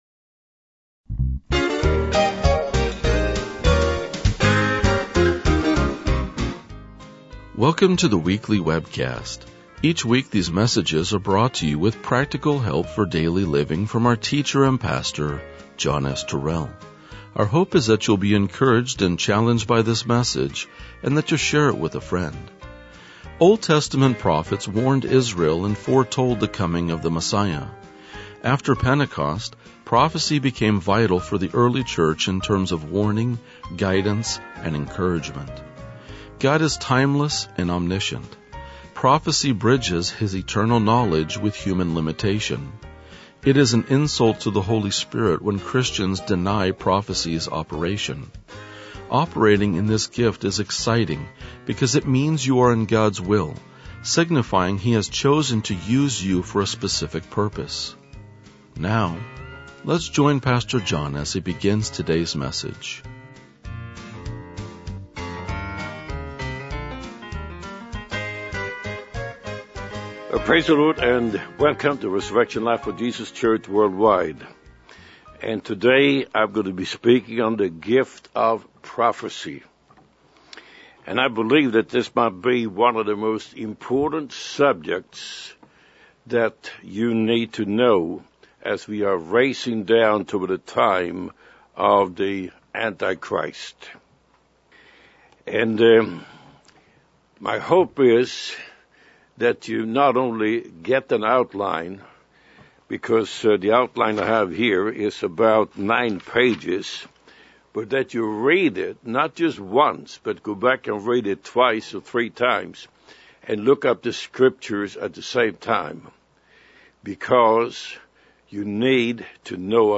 RLJ-2027-Sermon.mp3